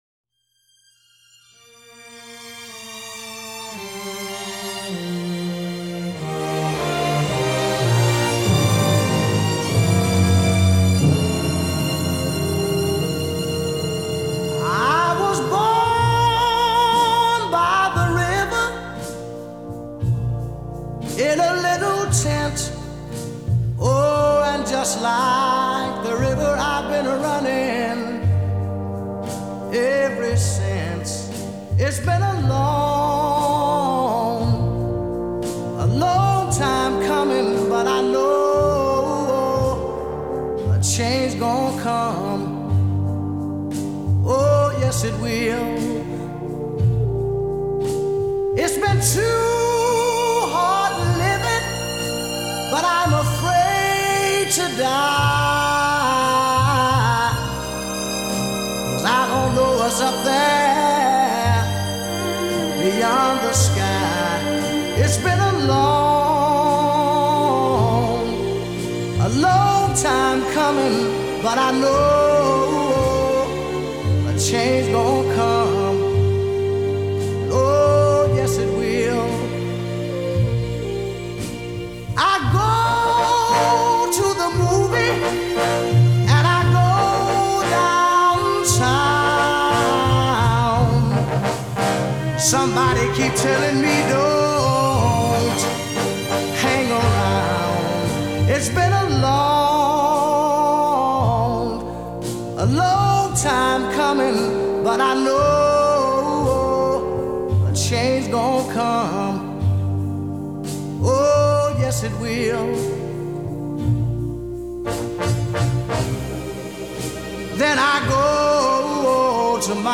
Genres: Soul